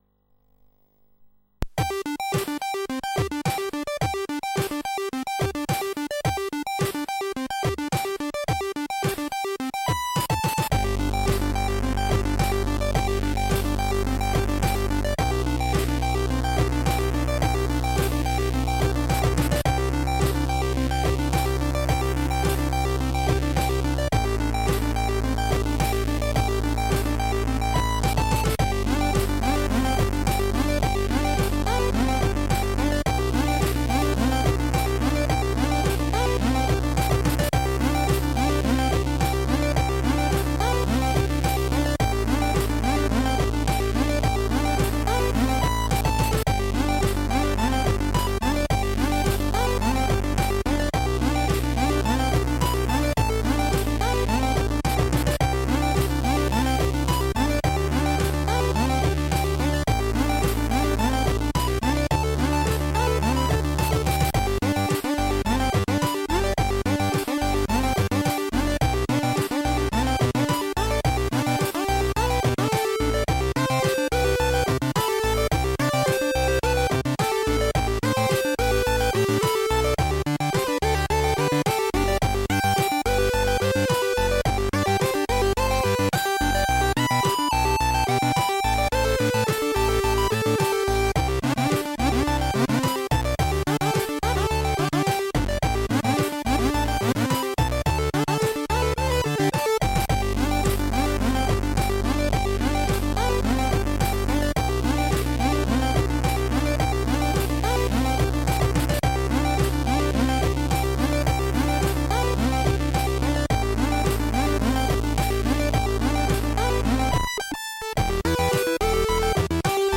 (C64C) (8580...